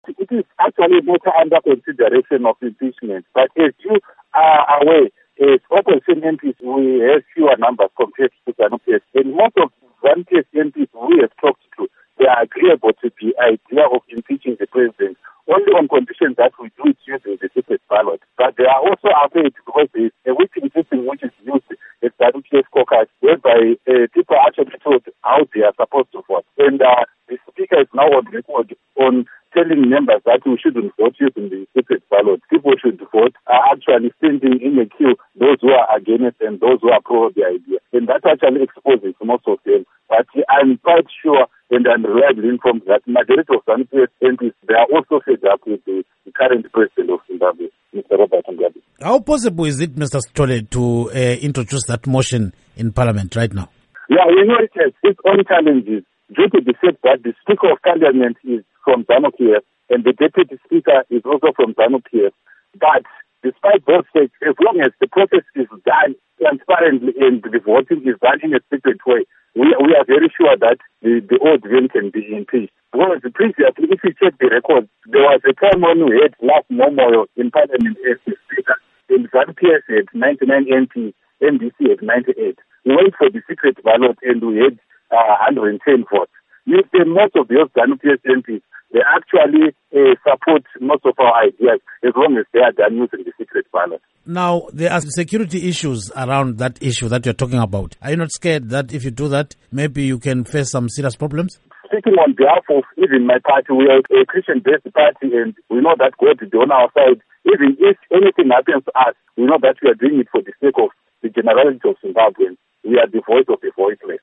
Interview With Godfrey Sithole on Mugabe Impeachment